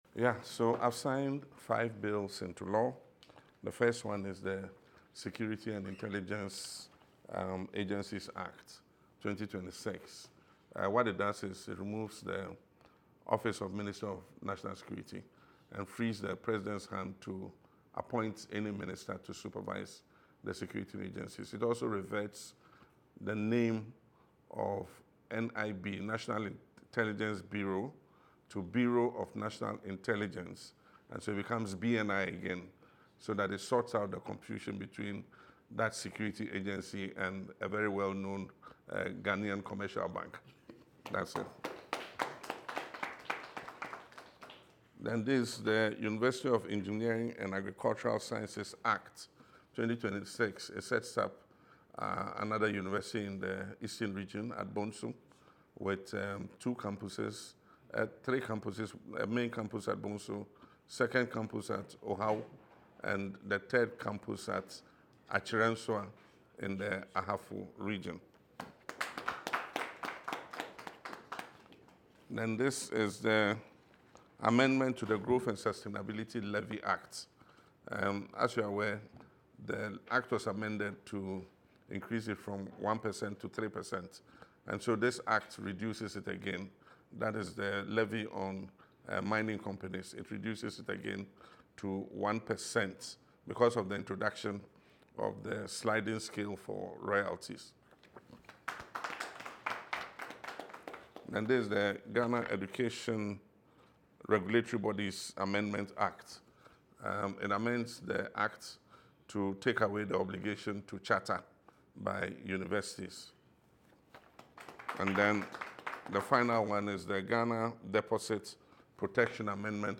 LISTEN TO PRESIDENT MAHAMA IN THE AUDIO BELOW: